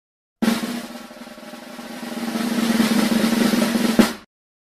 drum-roll-sound-effects-free-no-copyright-youtube-videoessentials.m4a